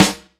Medicated Snare 39.wav